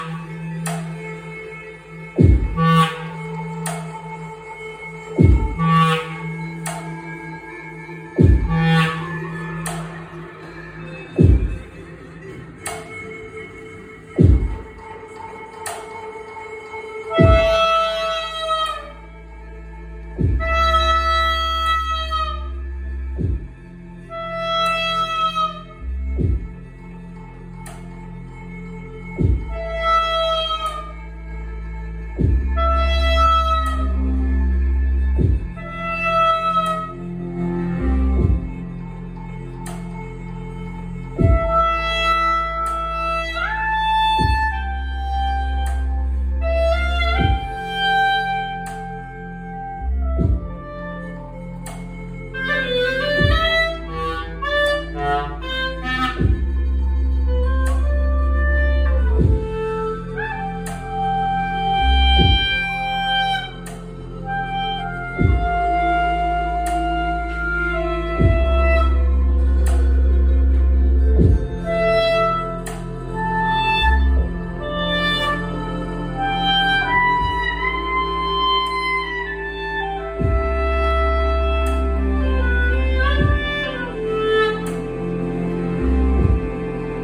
Hearing From An Unfolding Revolution Soundperformance
«Live